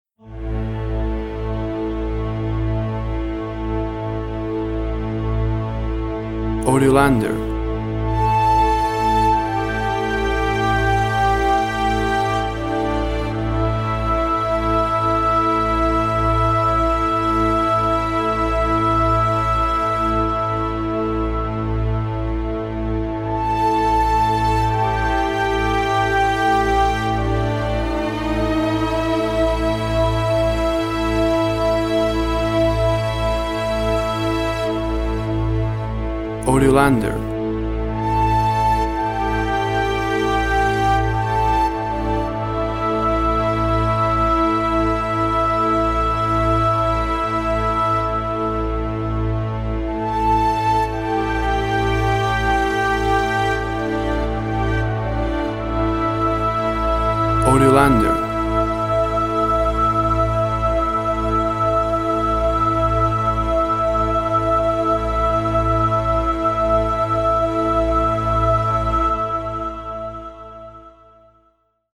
WAV Sample Rate 24-Bit Stereo, 44.1 kHz
Tempo (BPM) 84